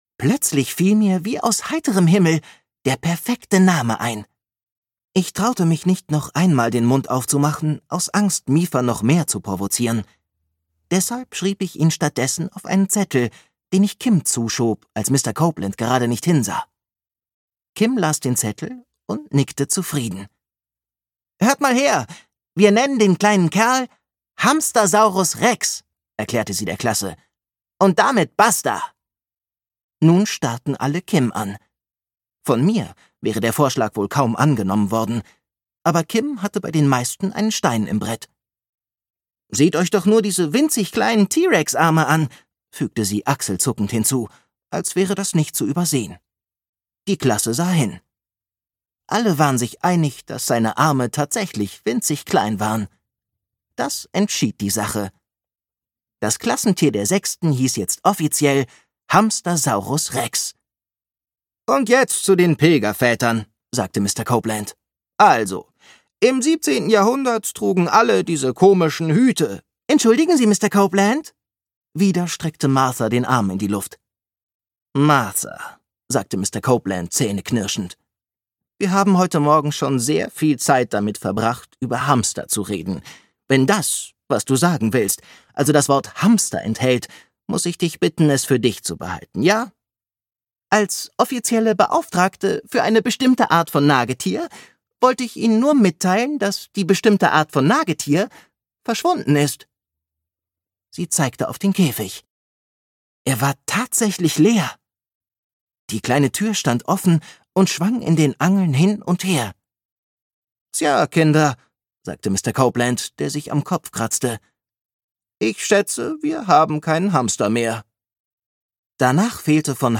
Hamstersaurus Rex 1: Hamstersaurus Rex - Tom O' Donnell - Hörbuch